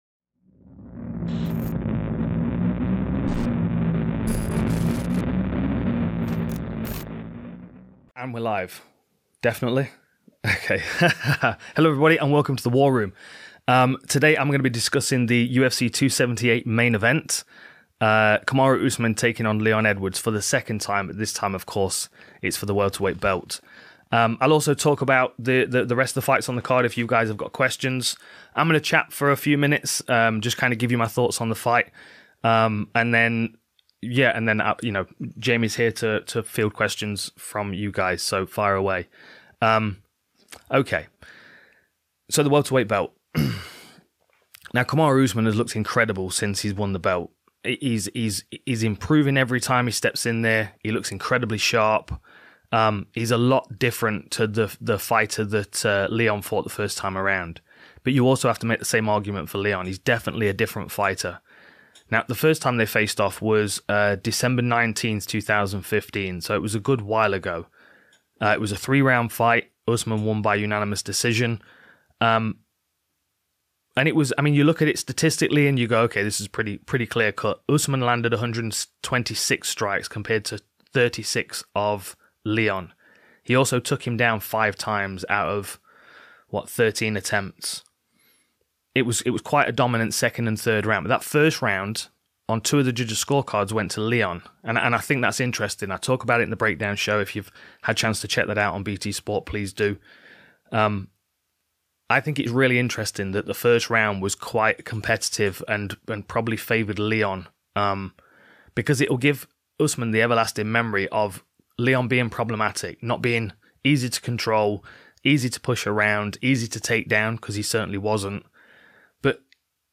The War Room, where Dan Hardy breaks down the UFC 278 Co-Main Event, Paulo Costa vs Luke Rockhold. Streamed Live from the Full Reptile HQ on August 18th, 2022.